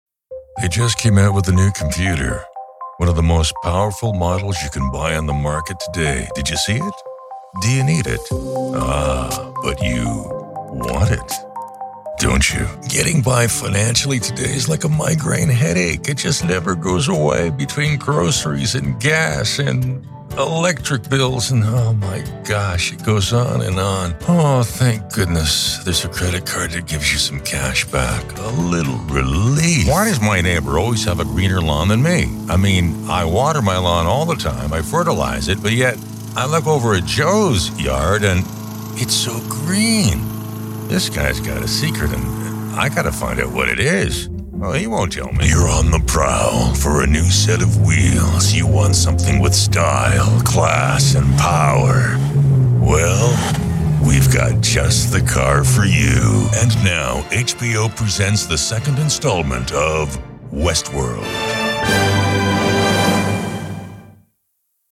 Inglés (Estados Unidos)
Cálido
Dominante
Amable